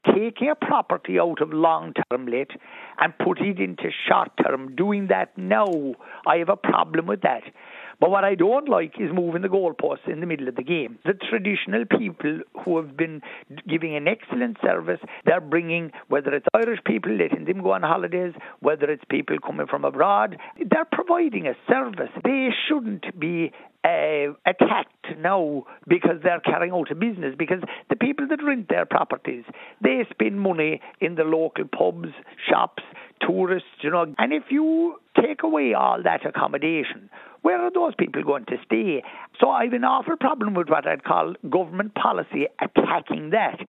Minister Healy-Rae says many rural properties won’t transfer to the long-term rental market……………